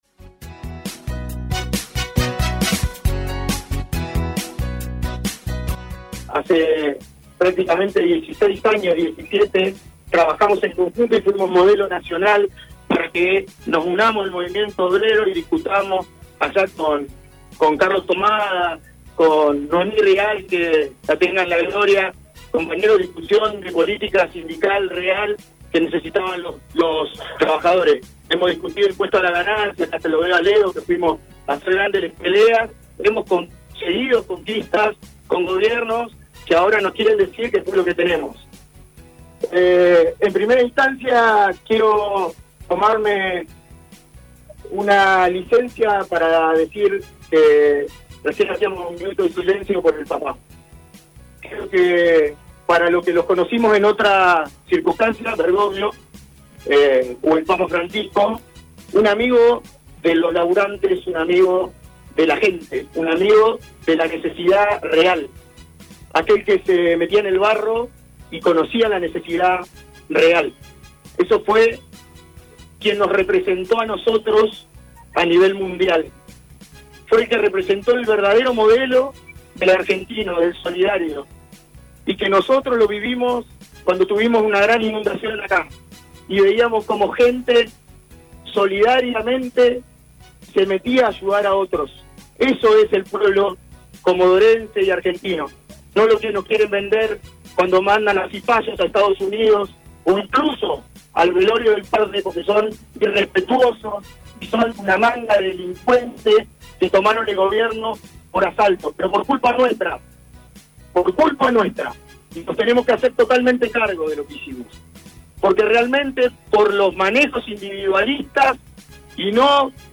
Convocados por la CGT adhirieron gran cantidad de gremios para participar del acto anticipado del 1ro de Mayo.